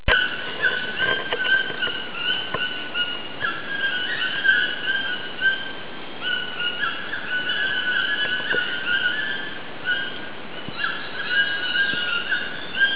Suoni della foresta:
il richiamo dei tucani
tucani.wav